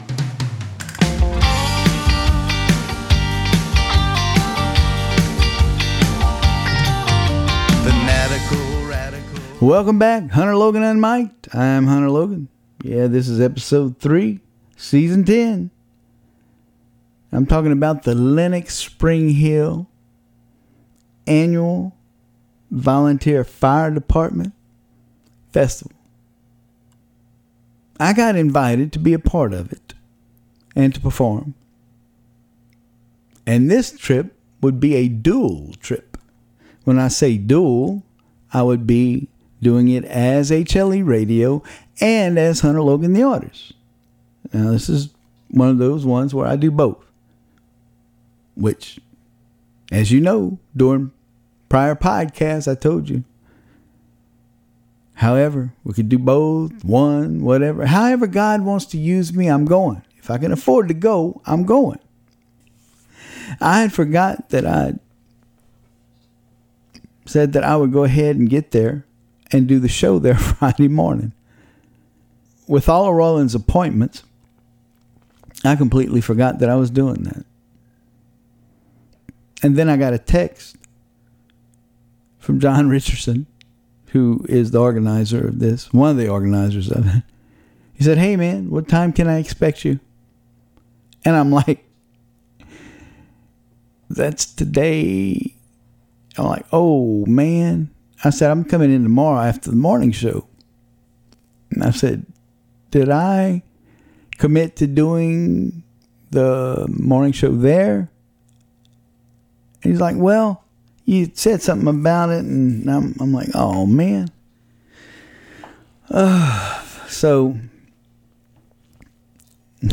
Doing a show in Lenox Al for the Lenox Volunteer Fire Dept.